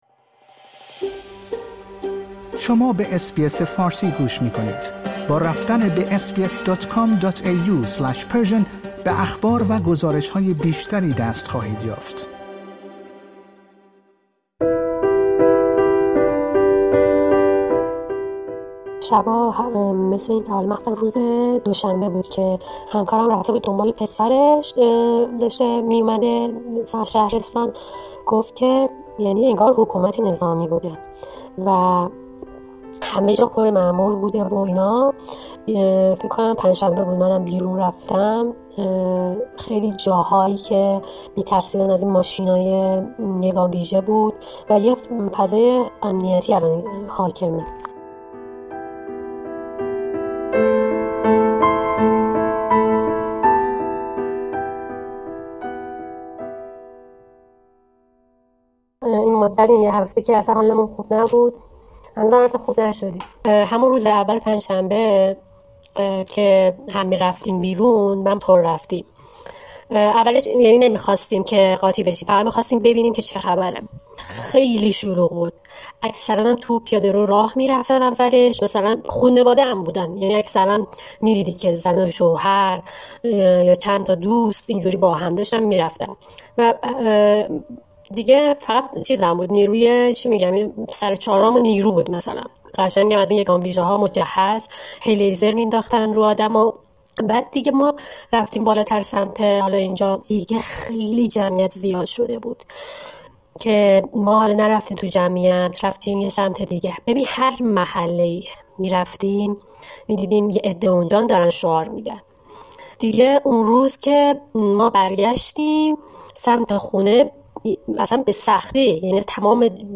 برای حفظ امنیت و هویت فرد مذکور اس‌بی‌اس فارسی نام او را در این گزارش معرفی نمی‌کند و صدای او را تغییر داده است.